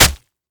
Flesh Chop 4 Sound
horror